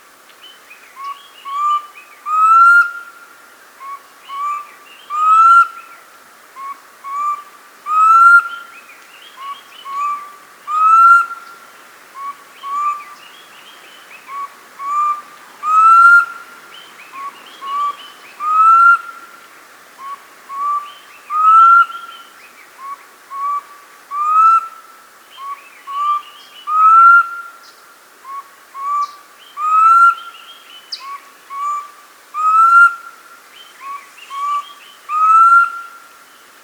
Now, here’s the Black Cuckoo (Cuculus clamosus), a southern African bird with a rather plaintive three-note call…(see photo at the top)